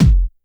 Kick_63.wav